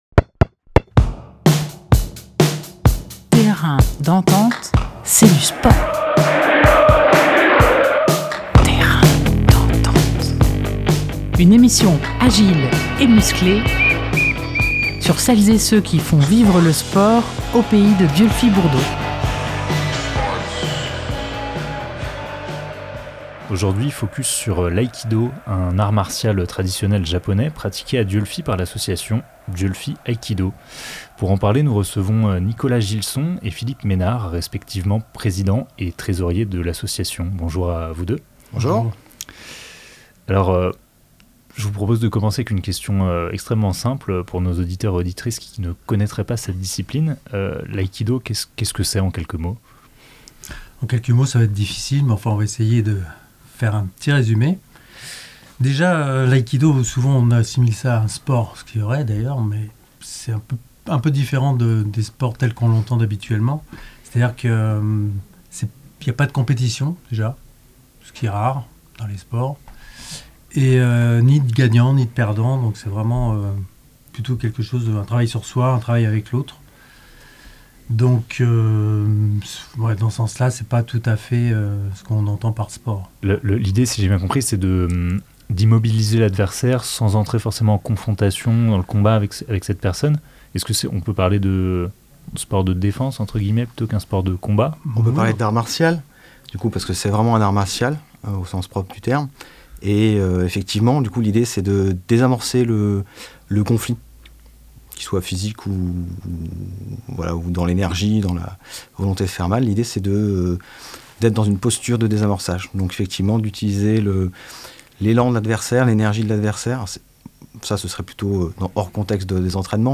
13 février 2023 16:30 | actus locales, Interview, Terrain d'entente